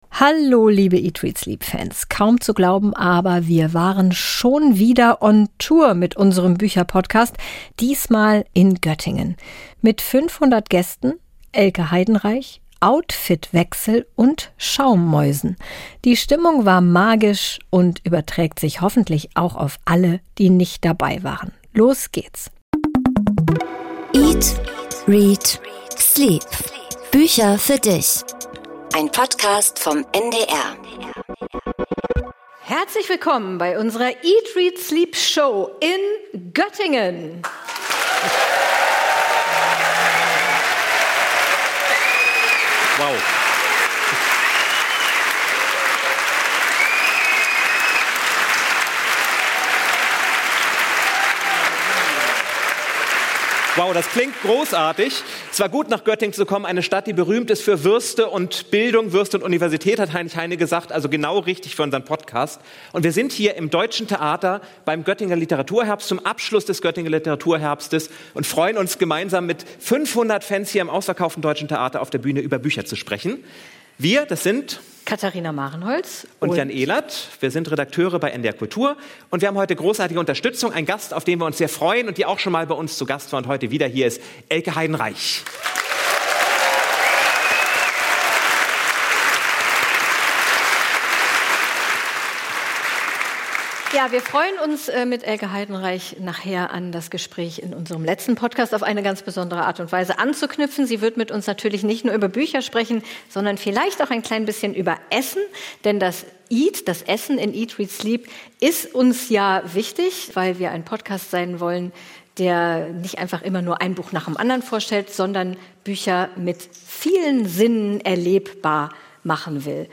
Ein Mitschnitt aus dem Deutschen Theater Göttingen. 500 Gäste waren bei der Podcast-Show dabei.